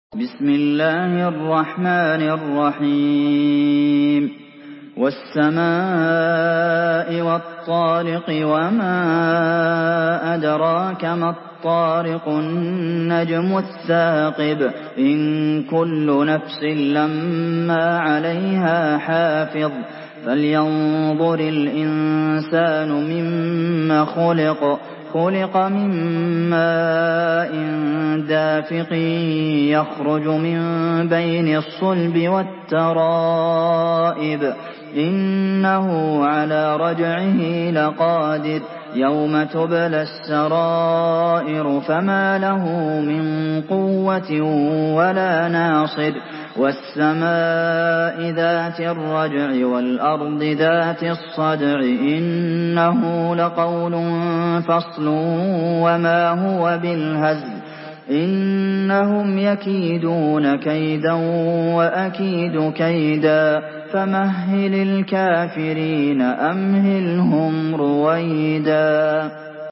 Surah Tarık MP3 in the Voice of Abdulmohsen Al Qasim in Hafs Narration
Surah Tarık MP3 by Abdulmohsen Al Qasim in Hafs An Asim narration.
Murattal Hafs An Asim